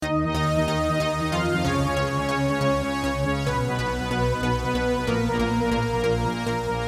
合成低音
描述：合成器低音140bpm。
Tag: 140 bpm Dance Loops Synth Loops 1.16 MB wav Key : Unknown